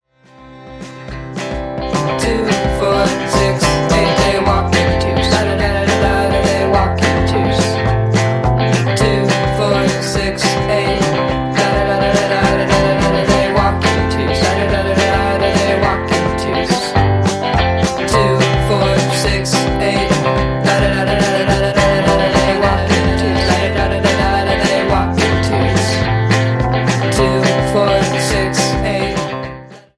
Indie / Alternativa